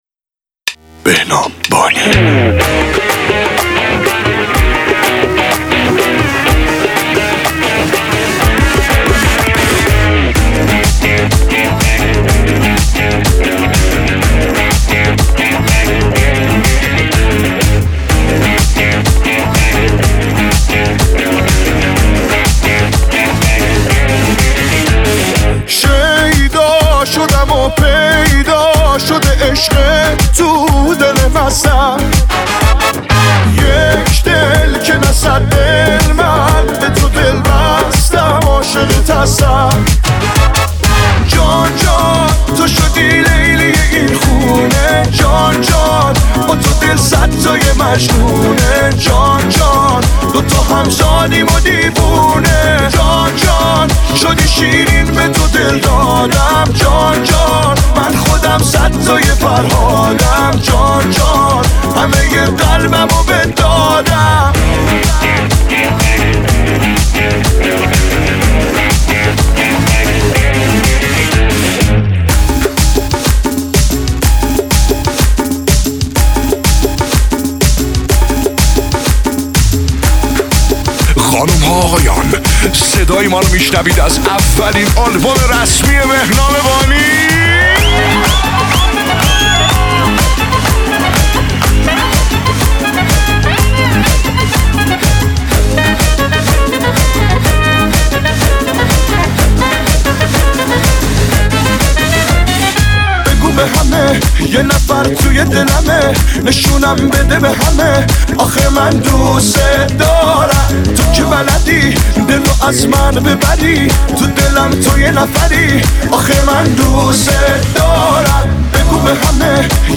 شاد رقصیدنی